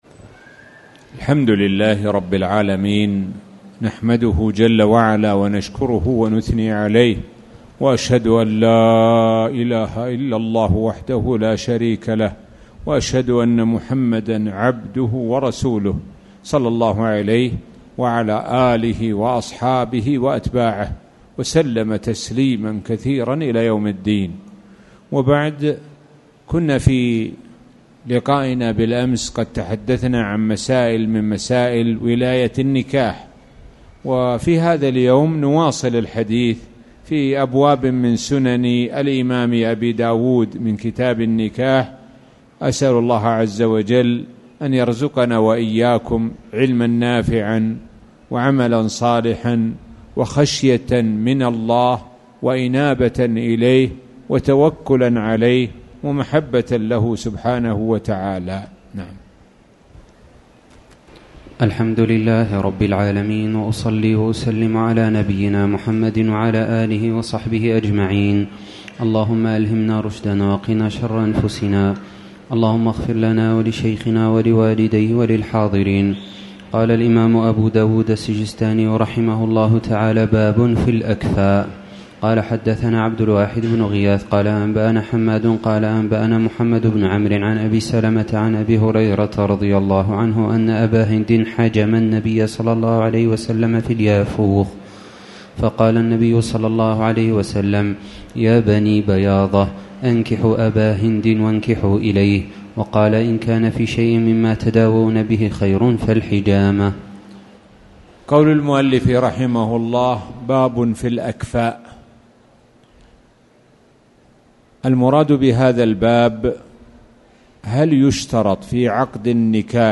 تاريخ النشر ٢٣ شوال ١٤٣٨ هـ المكان: المسجد الحرام الشيخ: معالي الشيخ د. سعد بن ناصر الشثري معالي الشيخ د. سعد بن ناصر الشثري كتاب النكاح